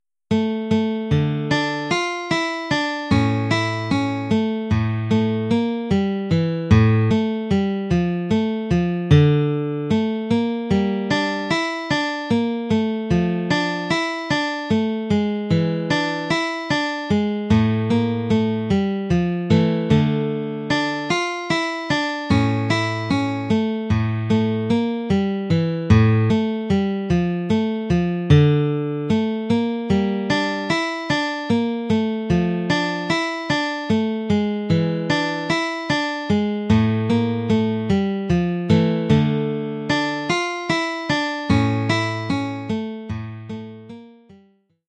Oeuvre pour guitare solo.